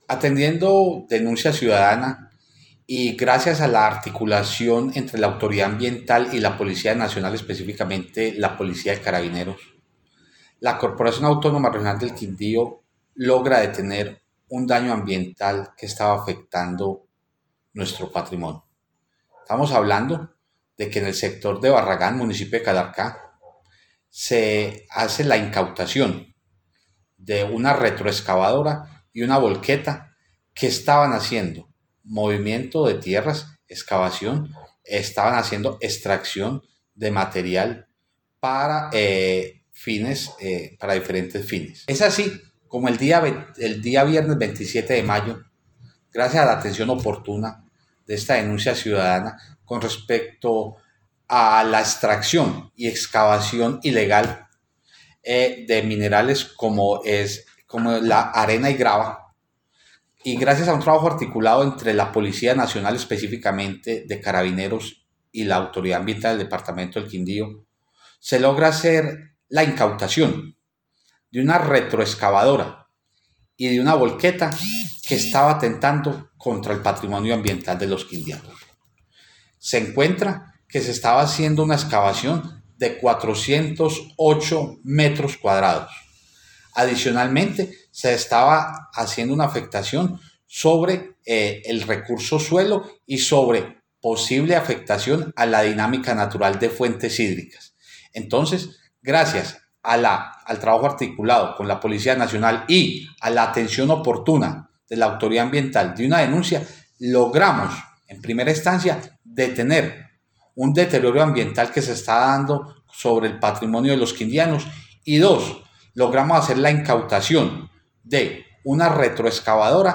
AUDIO: JOSÉ MANUEL CORTÉS OROZCO-DIRECTOR GENERAL DE LA CRQ